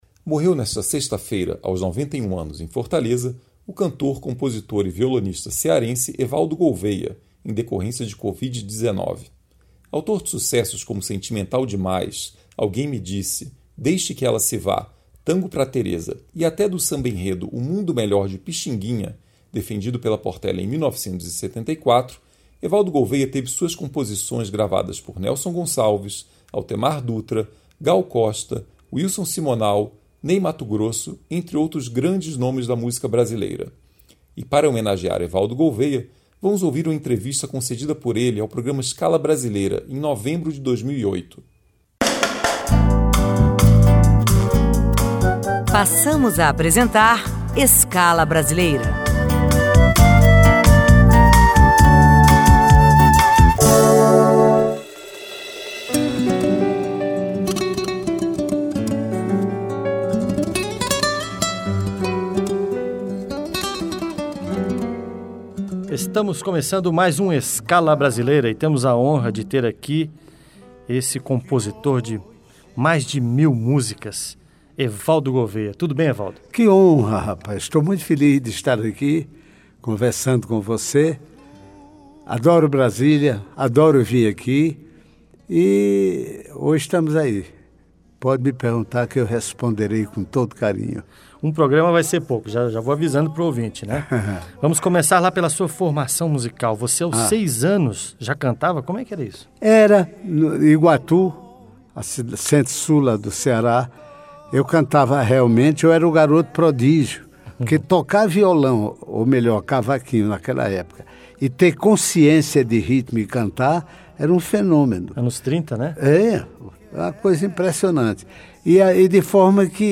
A Rádio Senado teve a honra de receber mais de uma vez Evaldo Gouveia em seus estúdios, onde o cantor e compositor cearense concedeu entrevista para o programa Escala Brasileira.